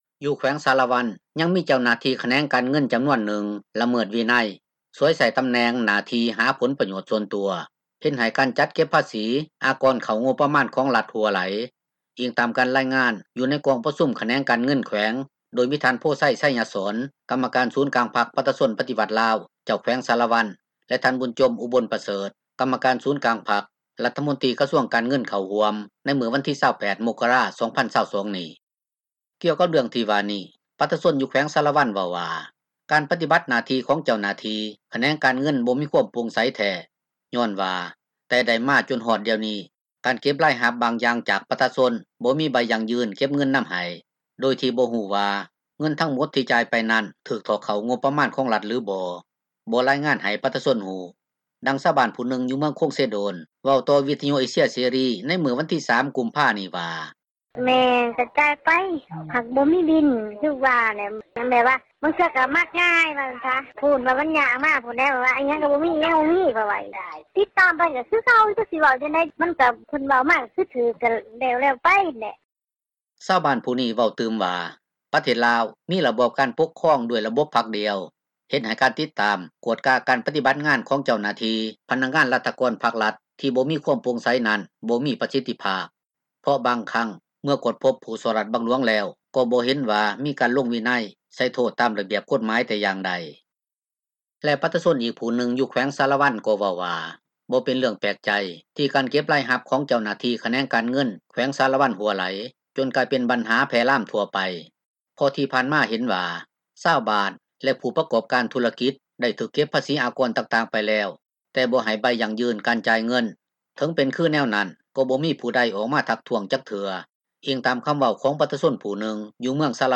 ນັກຂ່າວ ພົລເມືອງ
ດັ່ງຊາວບ້ານຜູ້ນຶ່ງ ຢູ່ເມືອງຄົງເຊໂດນ ເວົ້າຕໍ່ວິທຍຸເອເຊັຽເສຣີ ໃນມື້ວັນທີ 3 ກຸມພານີ້ວ່າ:
ດັ່ງເຈົ້າໜ້າທີ່ ສະພາປະຊາຊົນ ແຂວງສາຣະວັນ ທ່ານນຶ່ງກ່າວຕໍ່ ວິທຍຸເອເຊັຽເສຣີ ໃນມື້ວັນທີ 3 ກຸມພານີ້ວ່າ: